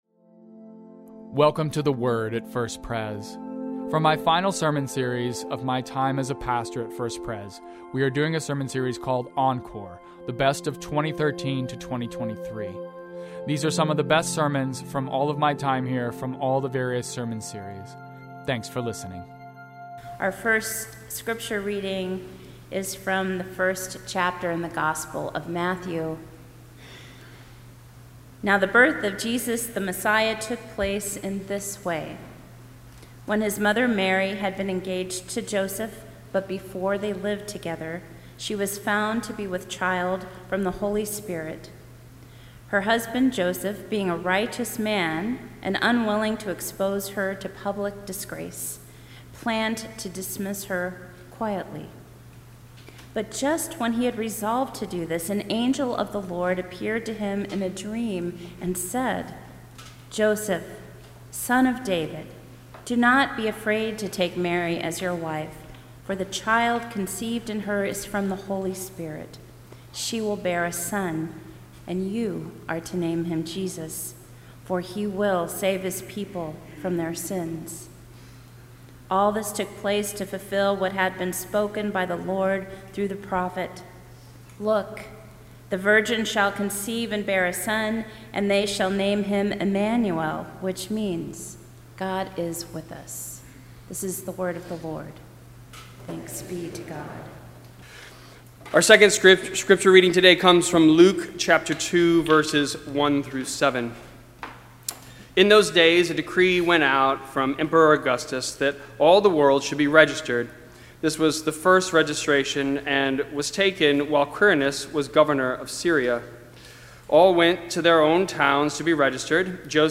Worship » Sermons » God in Art